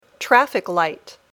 traffic-light.mp3